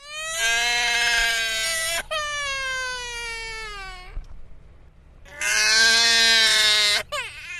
Baby Crying - Soun
Play Baby Crying - Soun Sound Button For Your Meme Soundboard!